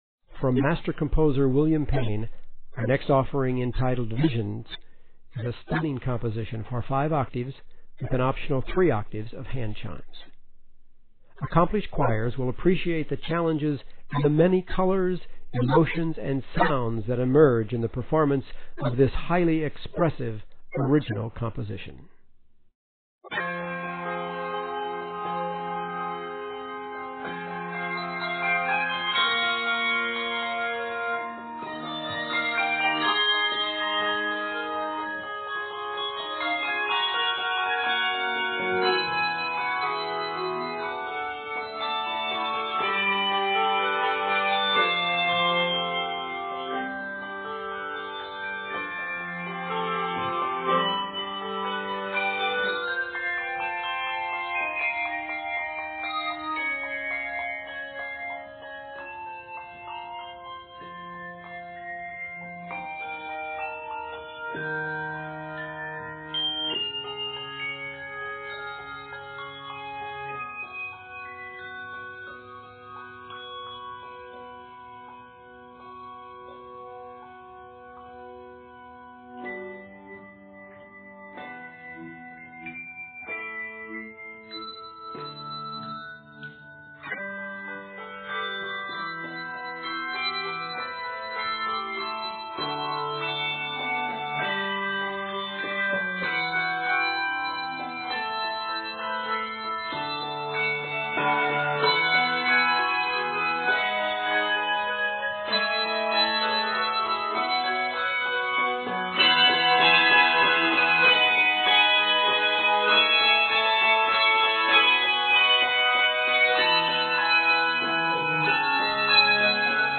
ethereal original work